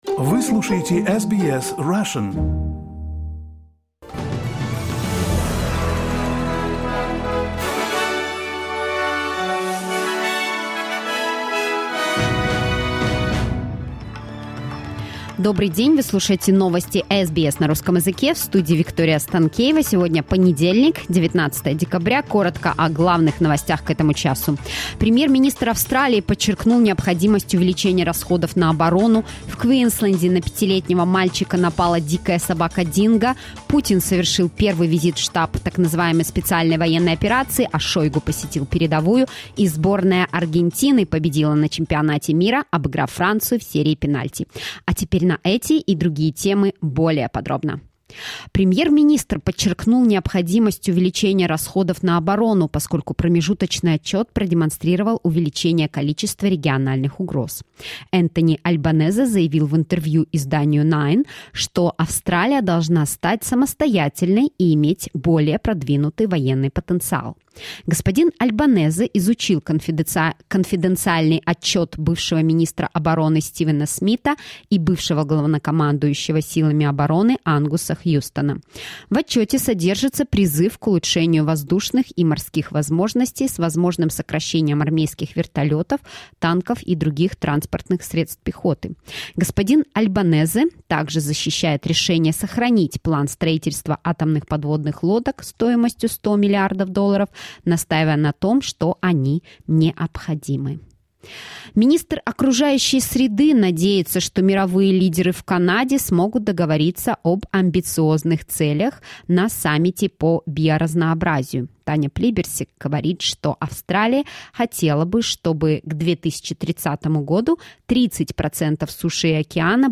SBS news in Russian — 19.12.2022